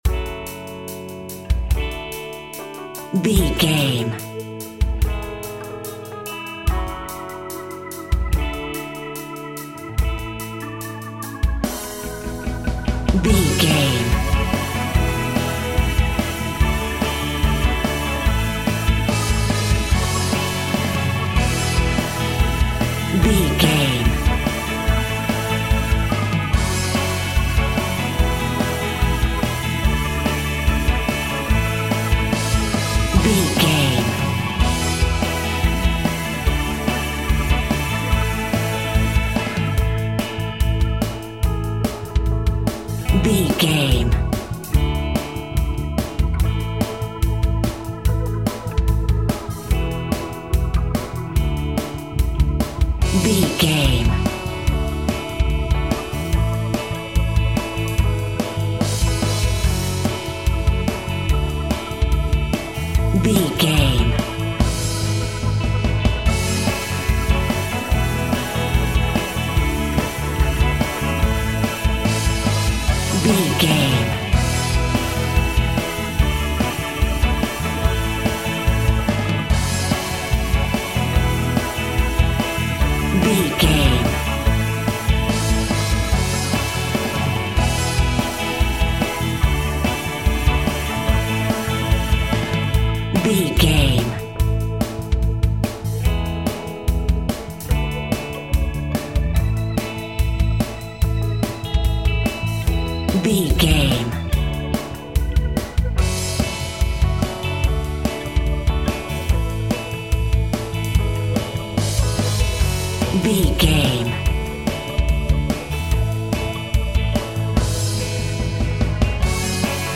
Pop Rock Upbeat Music Cue.
Ionian/Major
calm
happy
energetic
uplifting
electric guitar
bass guitar
drums
electric organ
piano
hammond organ